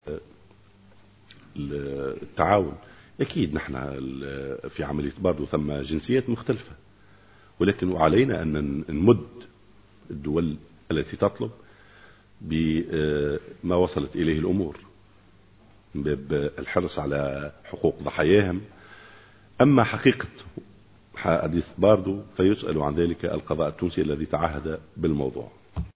أكد وزير الداخلية محمد الناجم غرسلي خلال ندوة صحفية اليوم الأحد أن الأخبار التي راجت عقب الهجوم الإرهابي على متحف باردو بخصوص مشاركة قضاة تحقيق جزائريين وفرنسيين في عملية التحقيق في أحداث باردو لا أساس لها من الصحة مؤكدا أن القضاء التونسي وحدة المخول بالنظر في هذه القضية مشيرا في السياق ذاته إلى أن تونس ستمد الدول بتفاصيل التحقيقات من باب حرصها على حقوق ضحاياها وفق قوله.